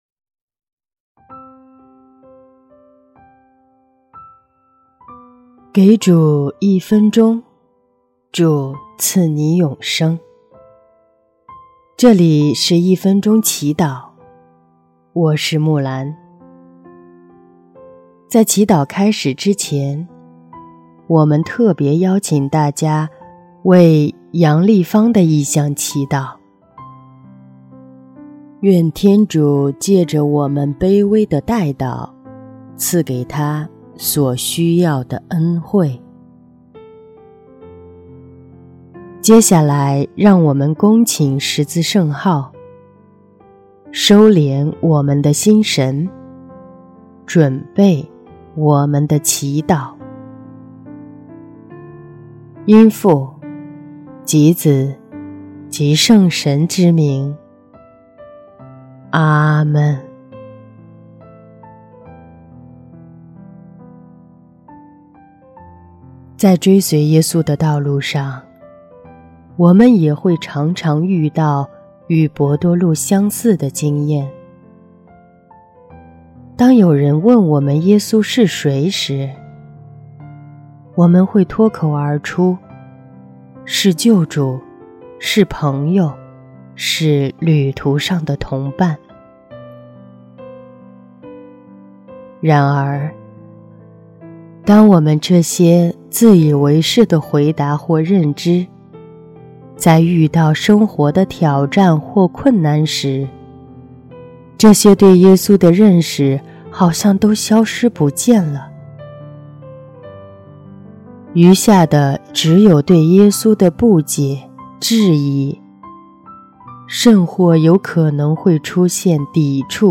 音乐：第三届华语圣歌大赛参赛歌曲《天上的力量》